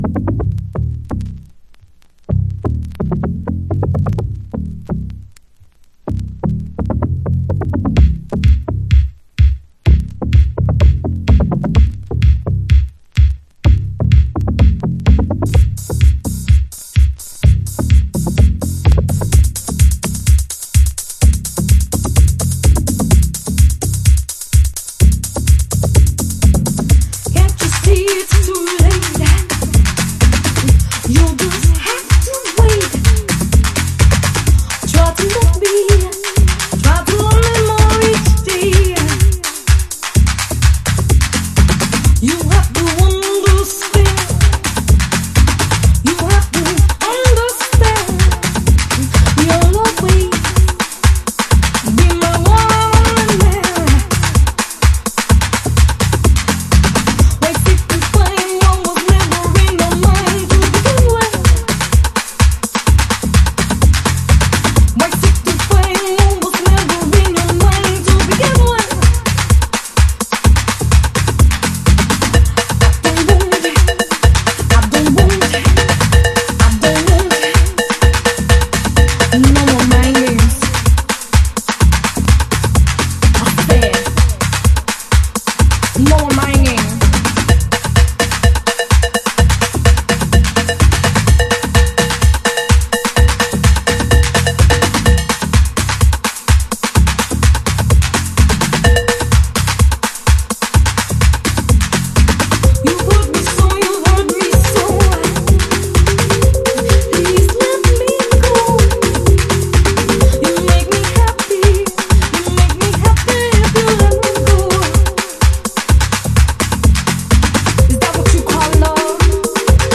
鉄板の喘ぎが入った助平CHI-HOUSE CLASSIC。
Chicago Oldschool / CDH